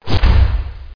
shutdoor.obj
1 channel